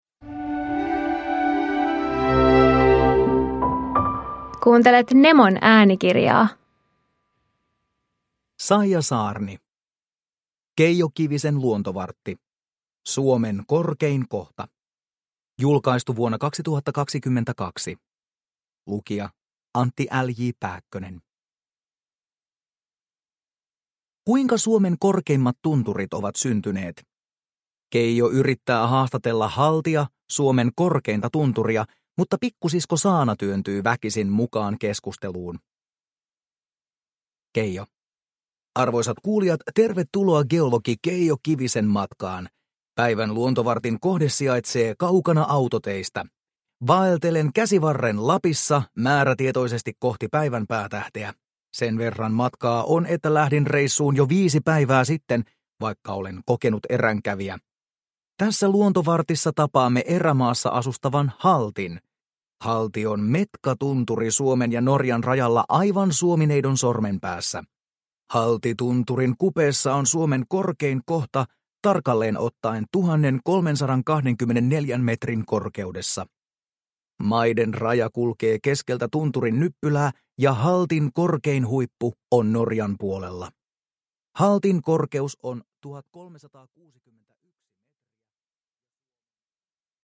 Suomen korkein kohta – Ljudbok – Laddas ner